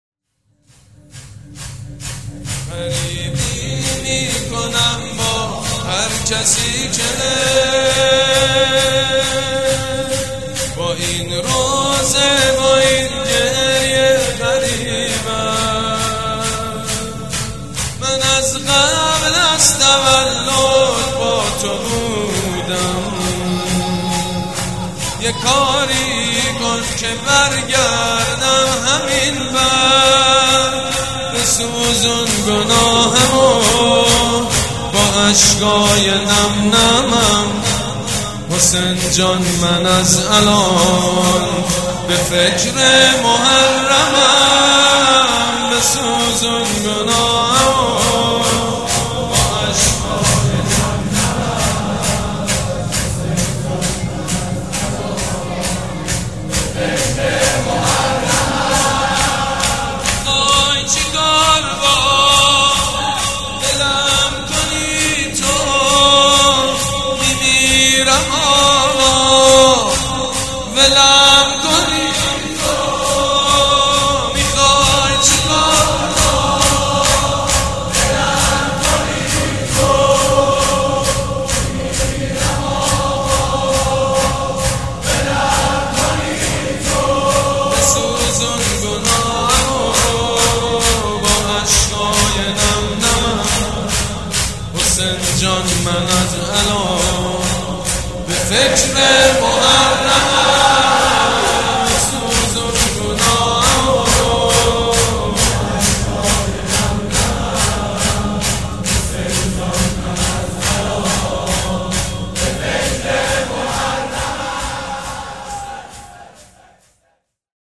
شور
مداح
حاج سید مجید بنی فاطمه
شهادت امام جواد (ع)